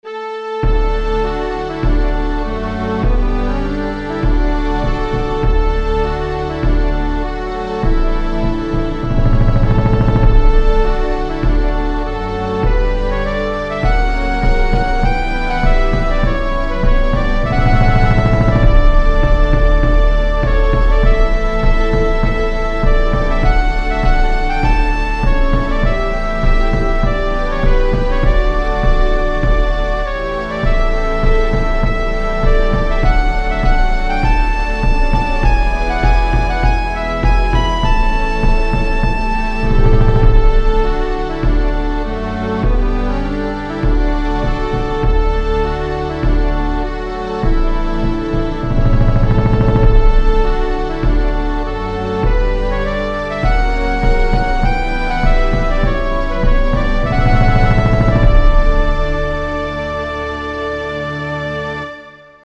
national anthem